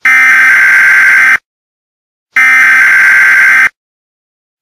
easAlarm.ogg